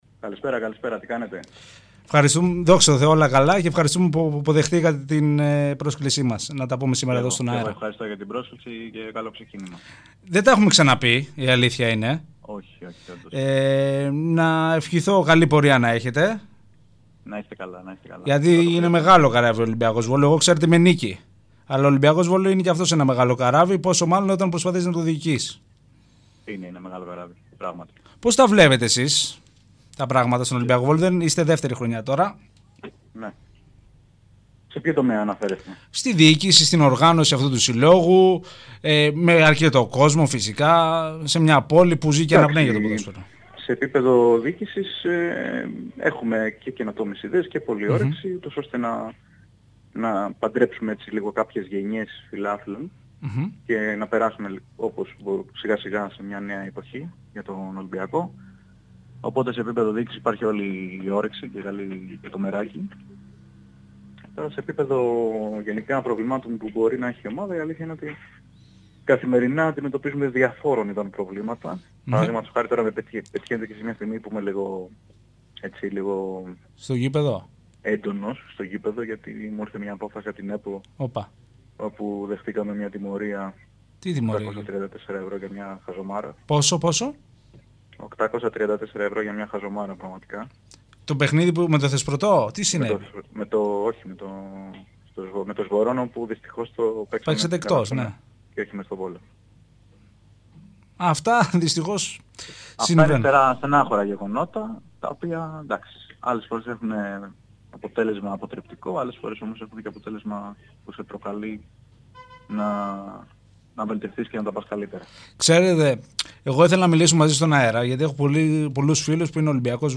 Ραδιοφωνική τοποθέτηση
Στο ΝΕΟ 103.3 FM